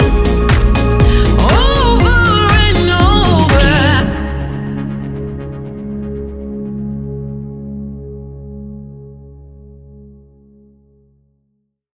Ionian/Major
C♭
house
electro dance
synths
techno
trance
instrumentals